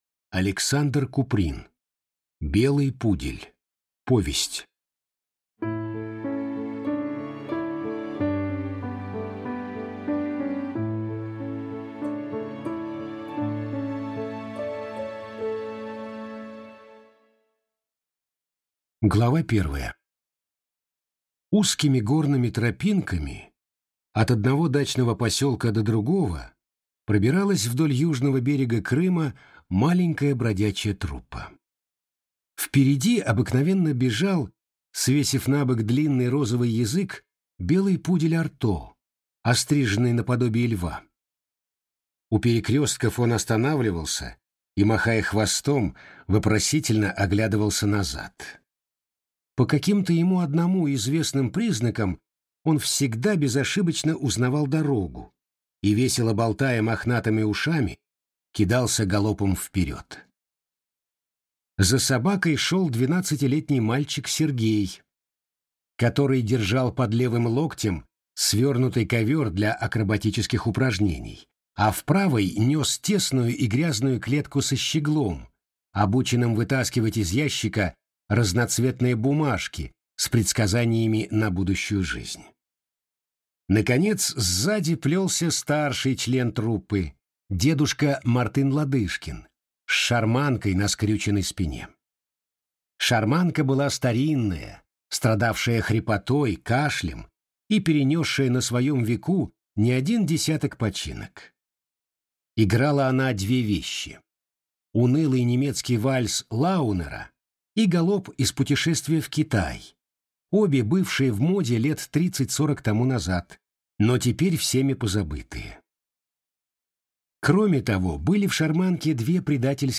Аудиокнига Белый пудель | Библиотека аудиокниг